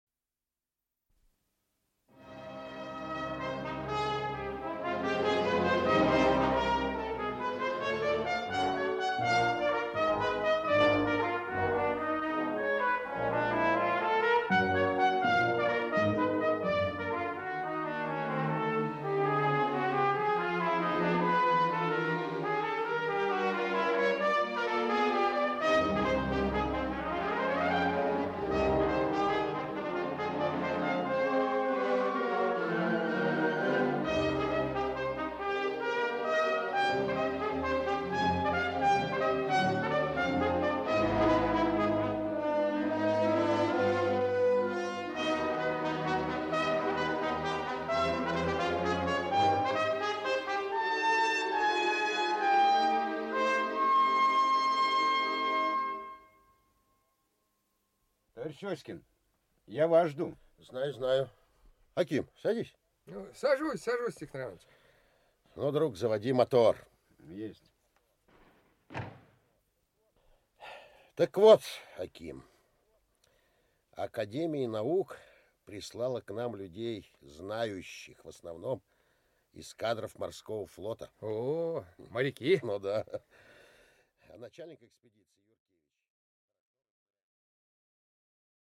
Аудиокнига Железный перстень. Глава 3 | Библиотека аудиокниг
Глава 3 Автор Зиновий Исаакович Фазин Читает аудиокнигу Актерский коллектив.